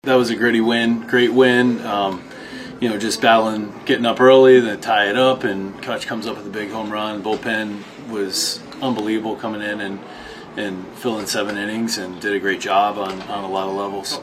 Manager Don Kelly says the Bucs worked hard for this one.